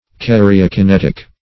Karyokinetic \Kar`y*o*ki*net"ic\
(k[a^]r`[i^]*[-o]*k[-i]*n[e^]t"[i^]k), a. (Biol.)